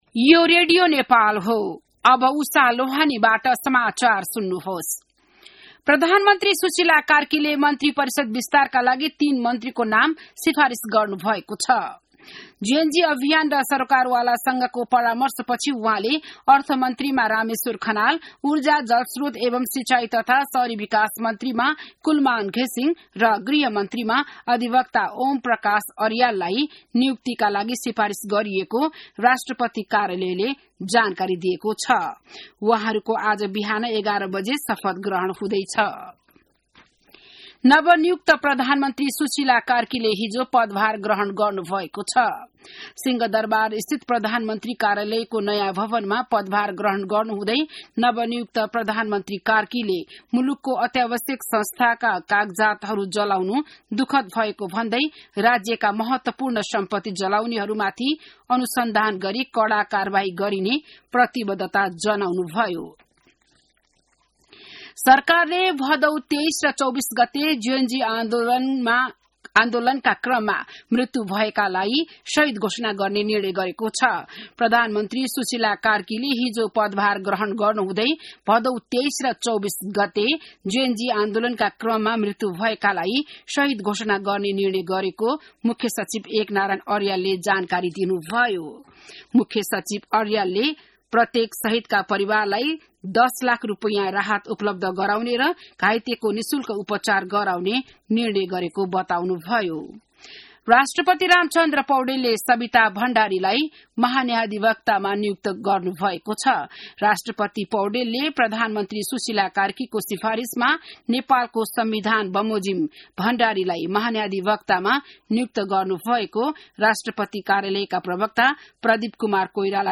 An online outlet of Nepal's national radio broadcaster
बिहान १० बजेको नेपाली समाचार : ३० भदौ , २०८२